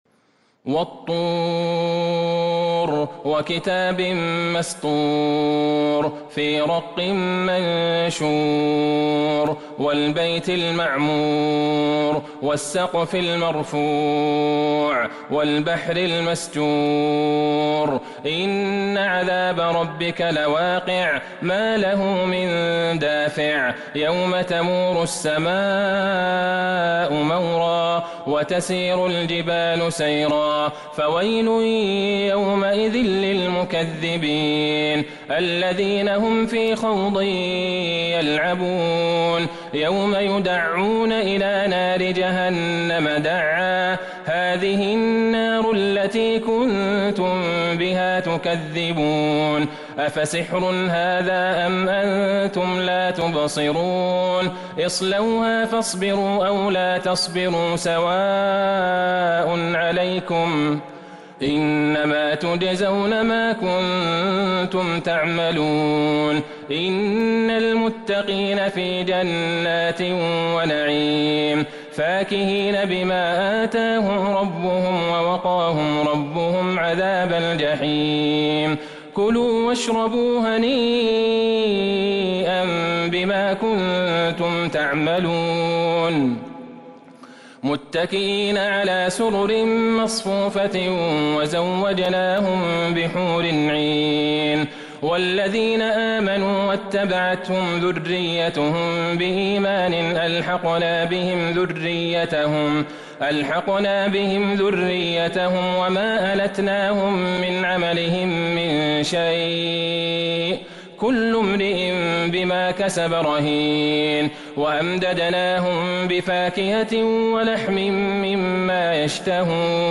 سورة الطور Surat At-Toor من تراويح المسجد النبوي 1442هـ > مصحف تراويح الحرم النبوي عام ١٤٤٢ > المصحف - تلاوات الحرمين